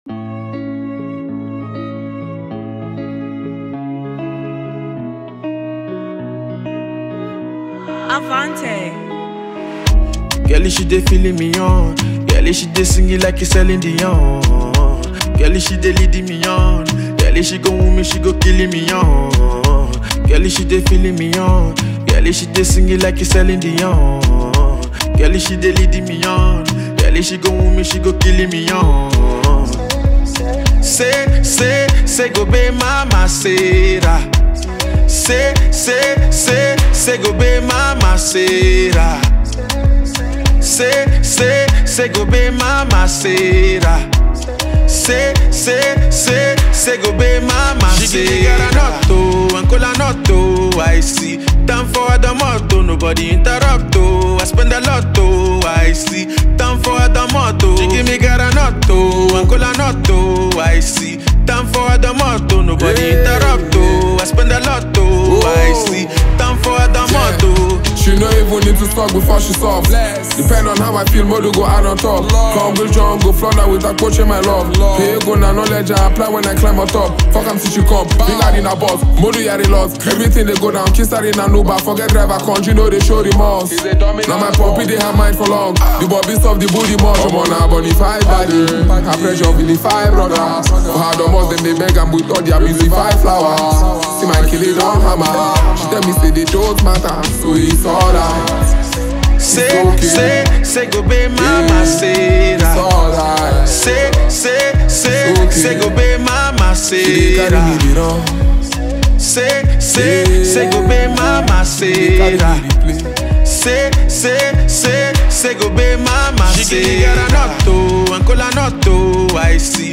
Buzzing fast-rising Nigerian music duo